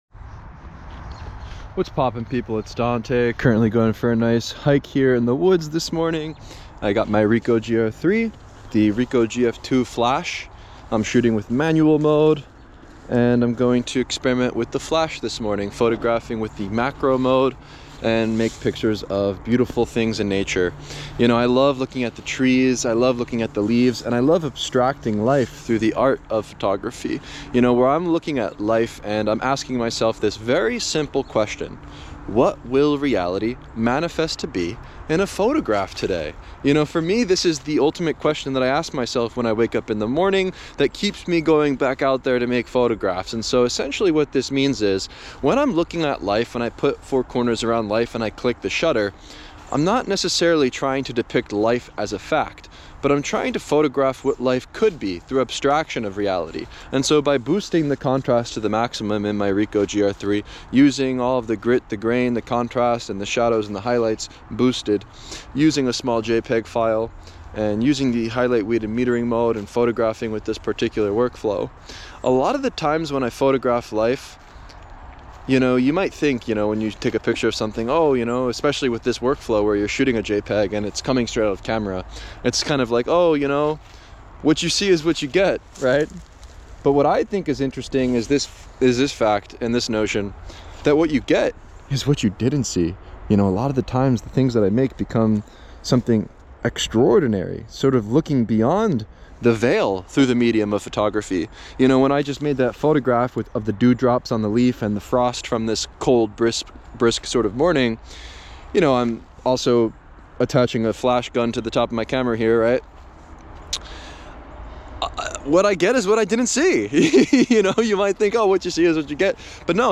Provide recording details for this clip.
This morning I’m out here hiking through the woods with my Ricoh GR III, the Ricoh GF-2 flash mounted on top, shooting in manual mode, experimenting with flash and macro, and just letting curiosity guide me.